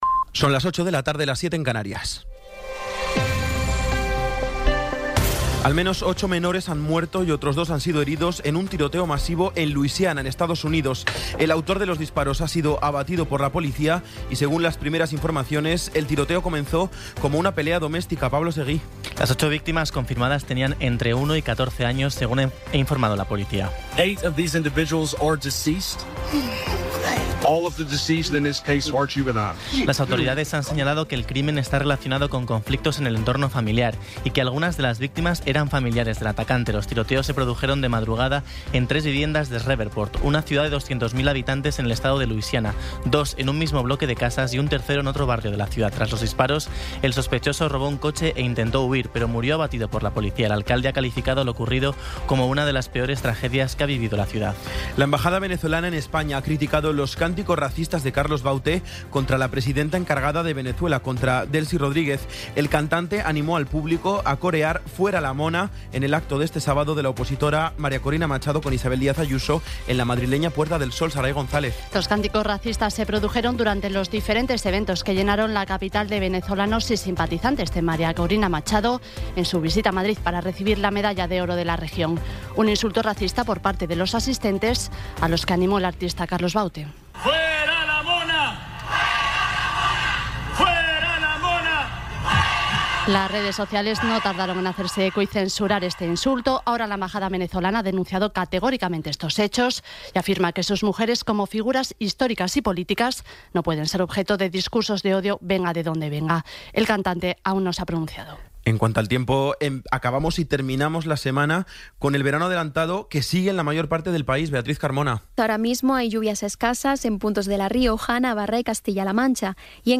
Resumen informativo con las noticias más destacadas del 19 de abril de 2026 a las ocho de la tarde.